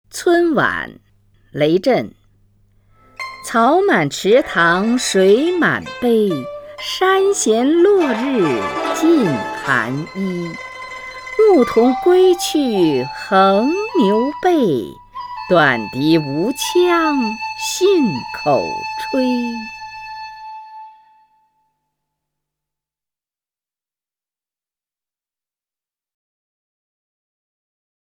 虹云朗诵：《村晚》(（南宋）雷震) （南宋）雷震 名家朗诵欣赏虹云 语文PLUS
（南宋）雷震 文选 （南宋）雷震： 虹云朗诵：《村晚》(（南宋）雷震) / 名家朗诵欣赏 虹云